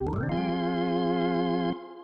Organ Roll.wav